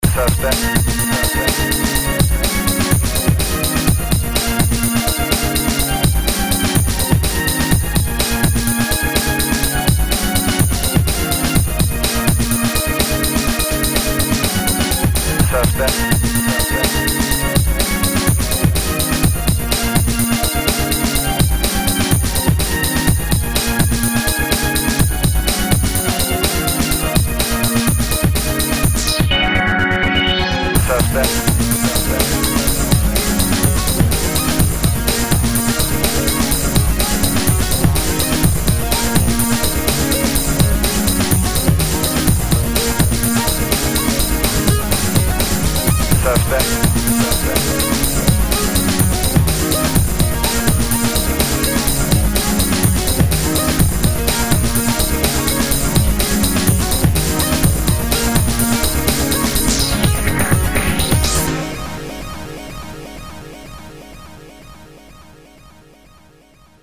Breakbeat